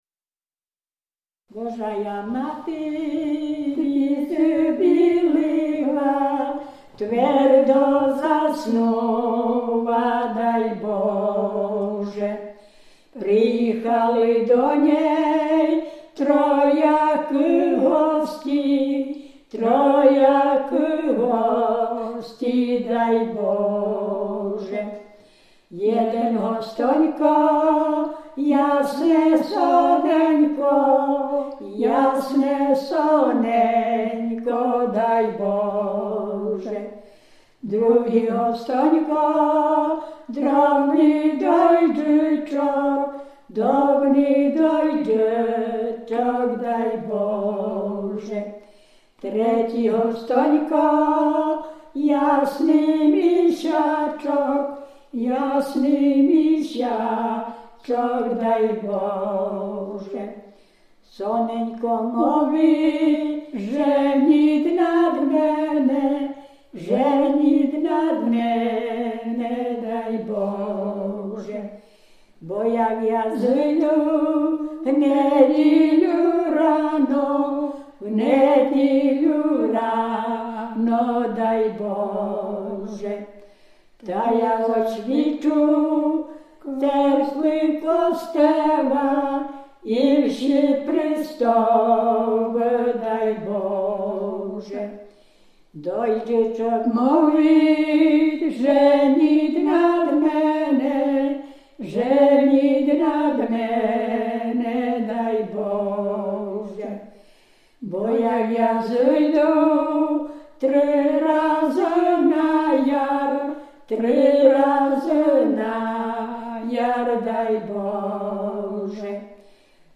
Dolny Śląsk, powat legnicki, gmina Kunice, wieś Piotrówek
Wiosenna
Łemkowie